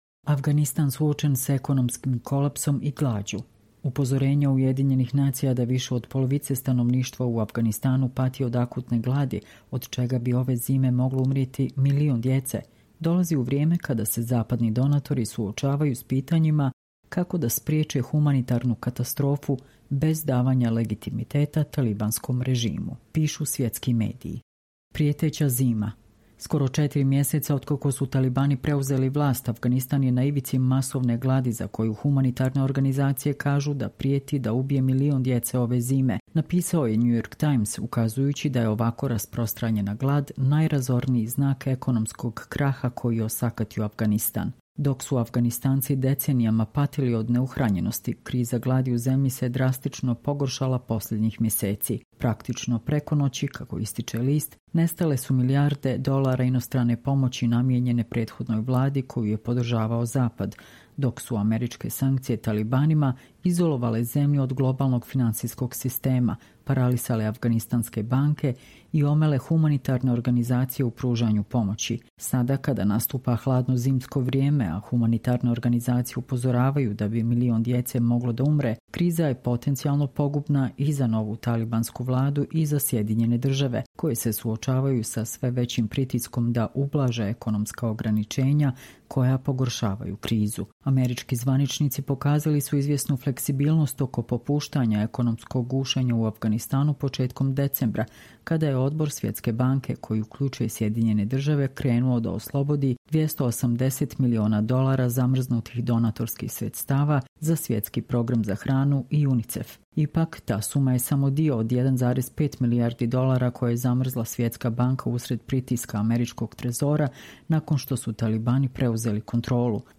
Čitamo vam: Afganistan suočen s ekonomskim kolapsom i glađu